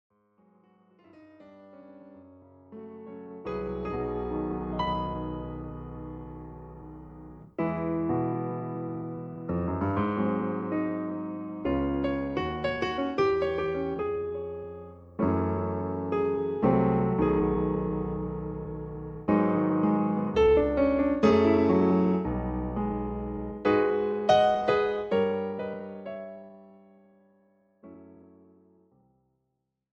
This is an instrumental backing track cover.
• Key – F
• Without Backing Vocals
• No Fade